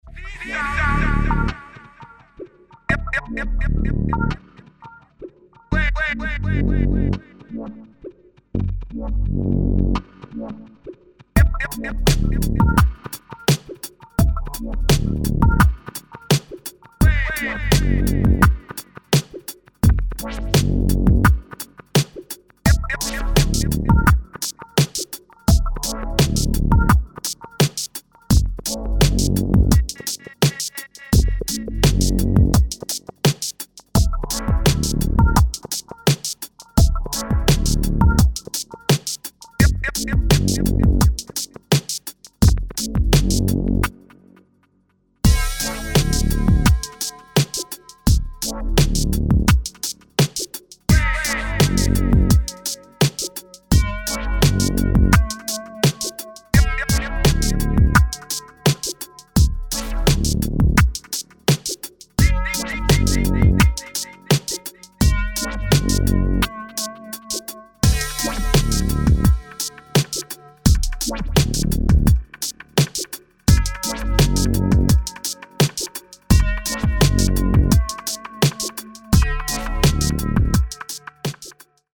Genres Minimal